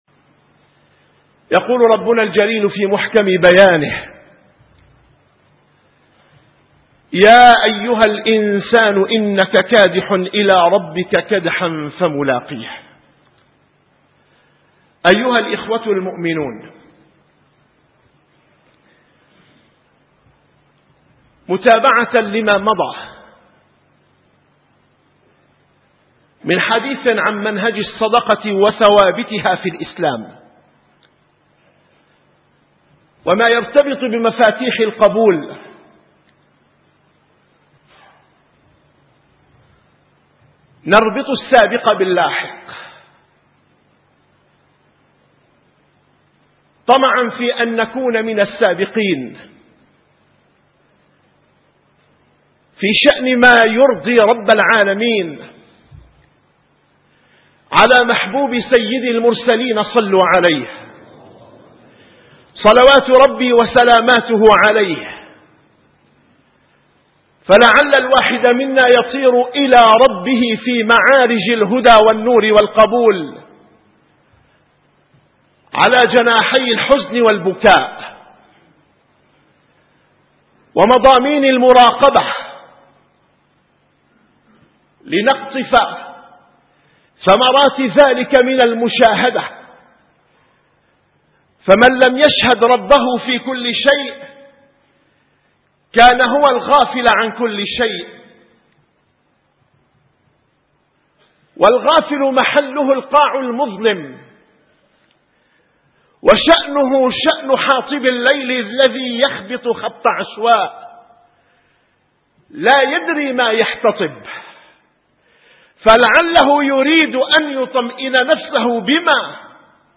- الخطب - خطبة